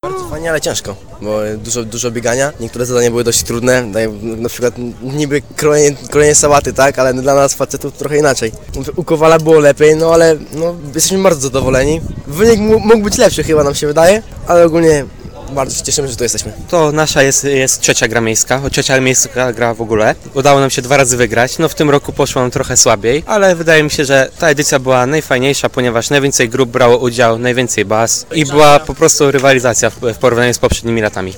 Zapytaliśmy uczestników zabawy o ich wrażenia.